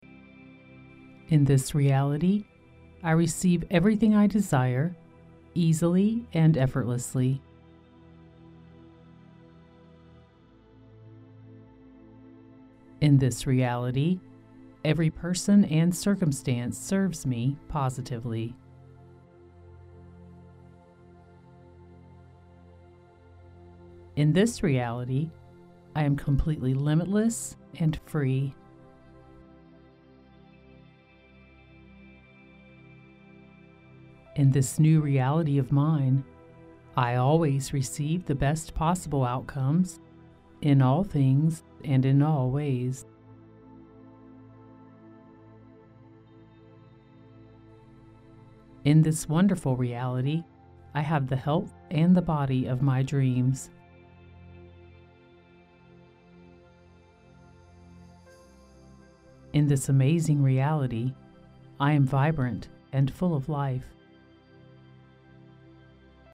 The meditation music combined with the shifting reality affirmations is a powerful theta track.